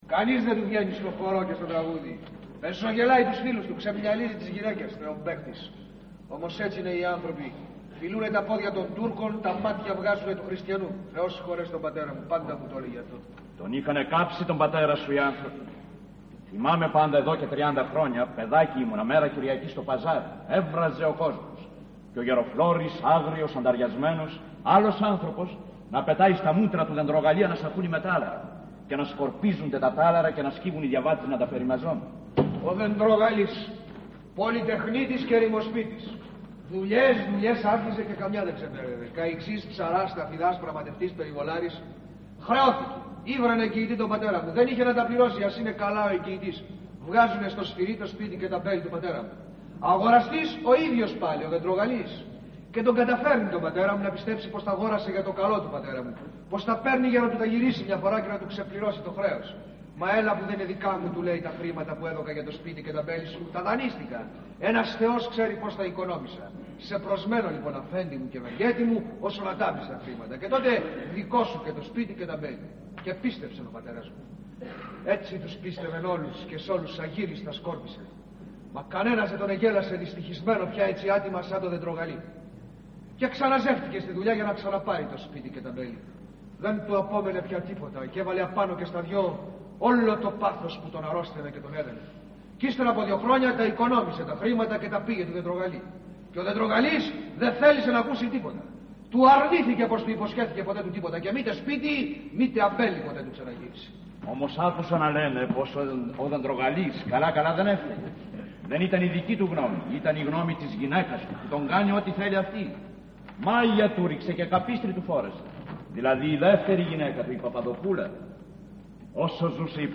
Ηχογράφηση Παράστασης
Αποσπάσματα από την παράσταση